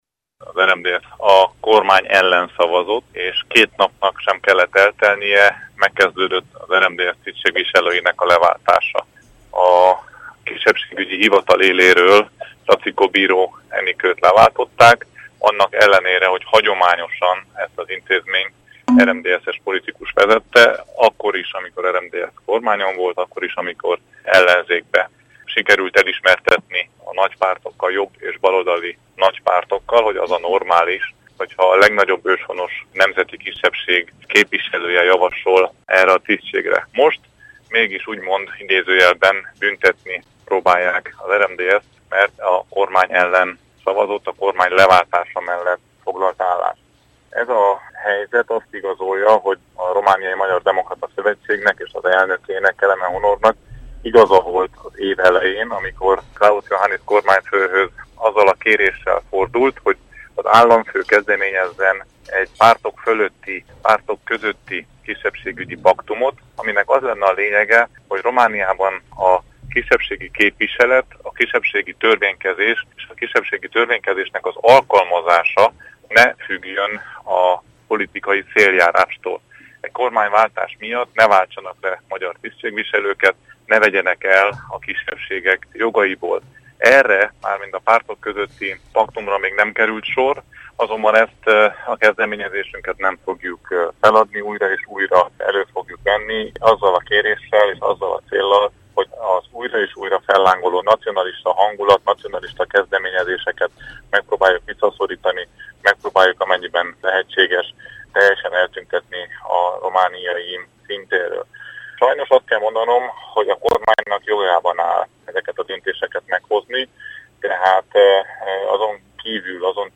Hallgassa meg Kovács Péter ügyvezető elnök nyilatkozatát.